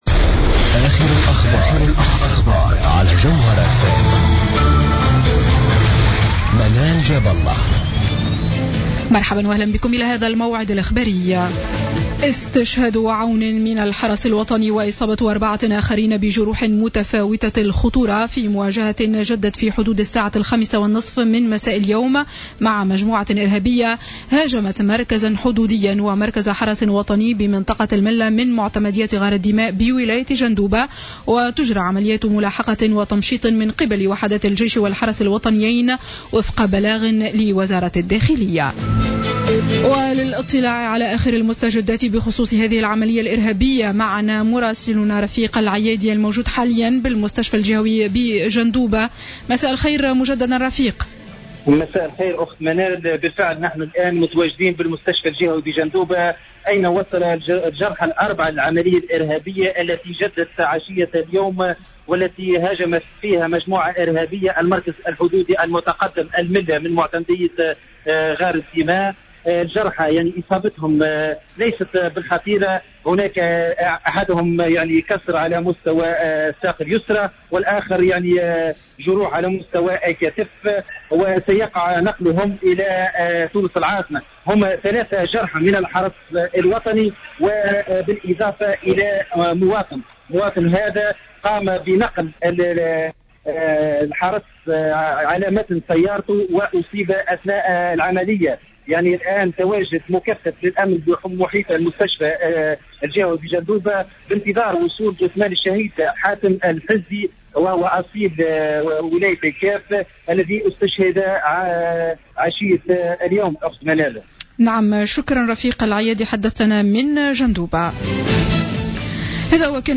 نشرة أخبار السابعة مساء ليوم الاثنين 15جوان 2015